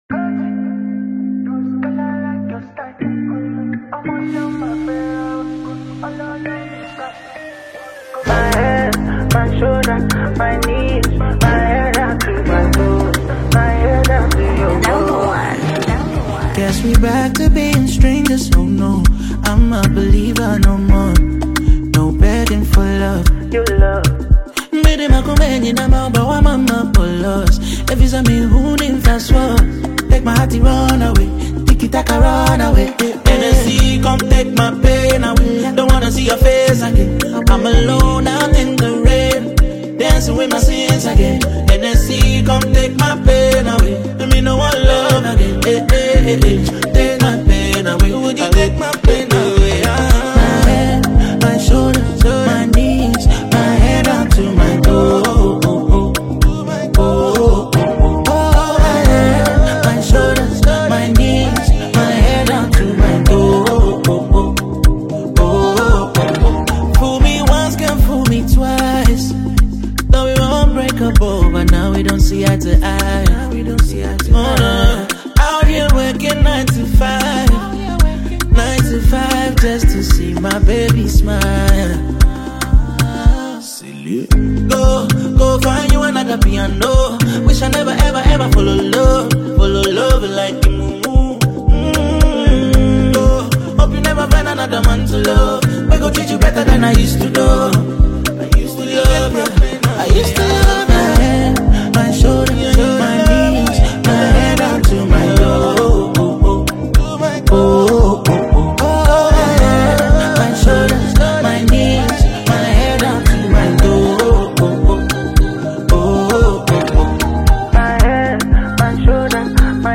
A groovy Afrobeat jam
filled with energy, melody, and dance-floor vibes.
infectious Afrobeat track